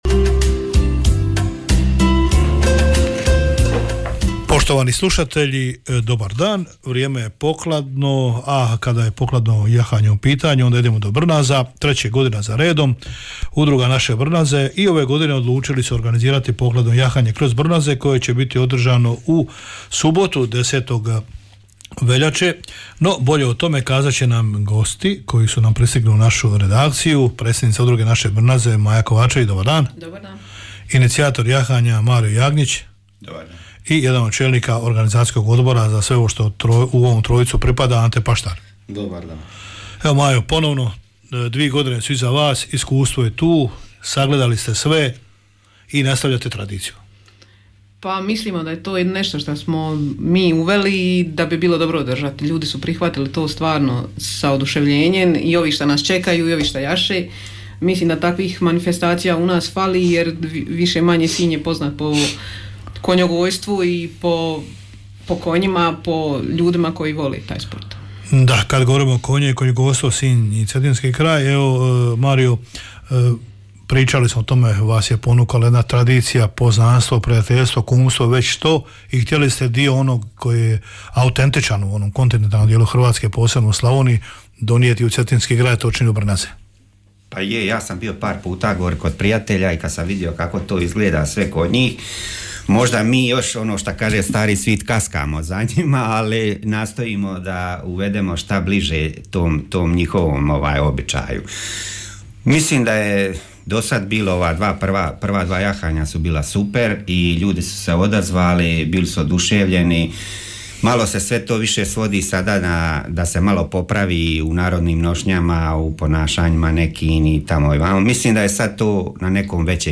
udruga-brnaze-gosti.mp3